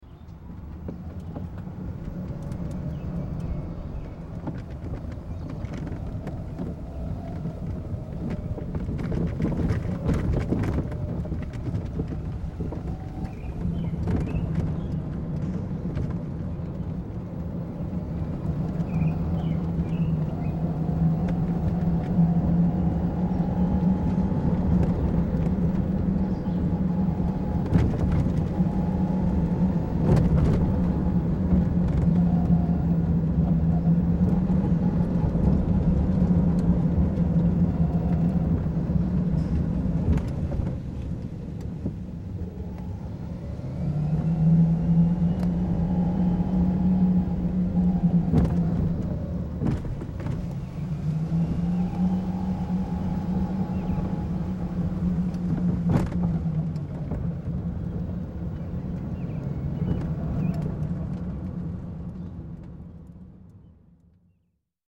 Звуки гольфа
Атмосфера катания на машинке по полю для гольфа